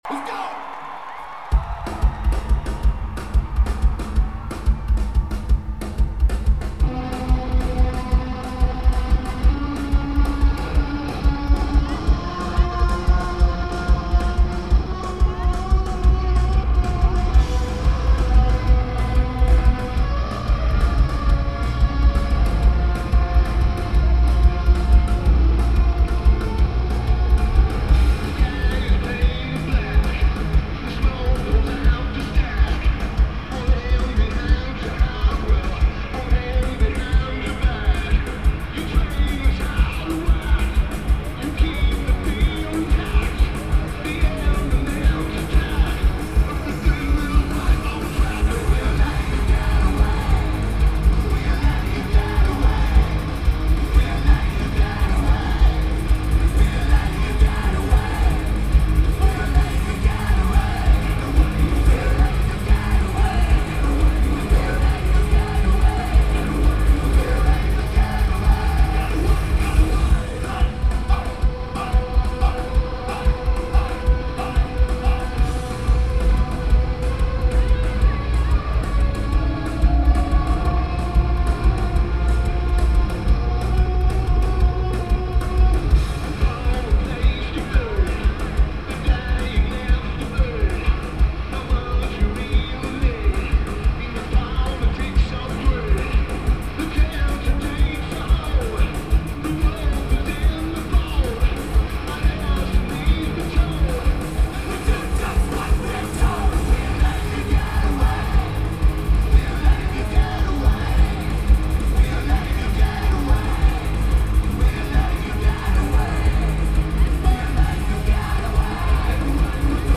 Rose Garden Arena
Lineage: Audio - AUD (CSB's + Sharp MD- MT15)